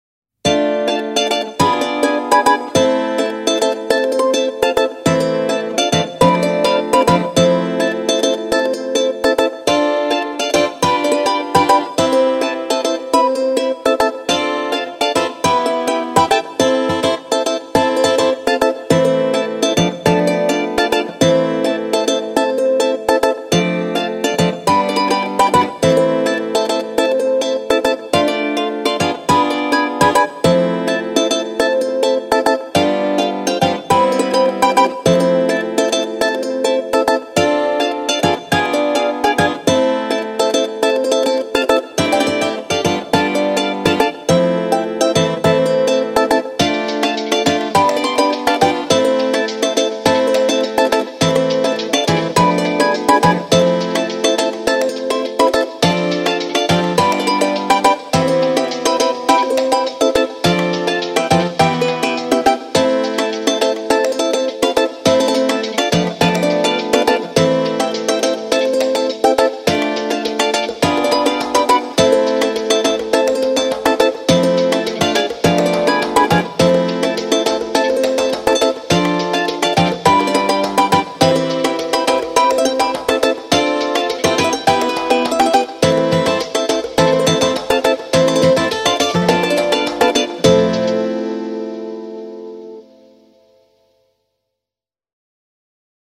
Largo [0-10] passion - ukulele - - -